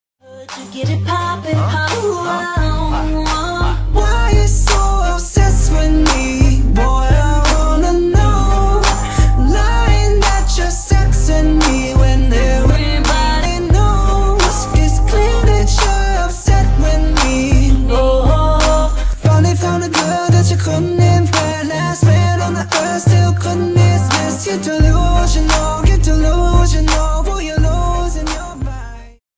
I used my own voice model to make this cover.